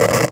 radio_walkie_talkie_static_04.wav